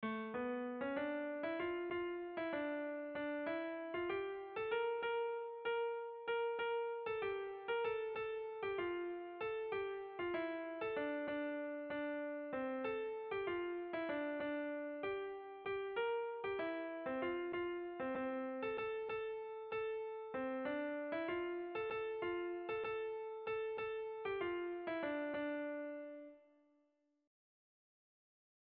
Bertso melodies - View details   To know more about this section
Sentimenduzkoa
Zortziko txikia (hg) / Lau puntuko txikia (ip)
ABDE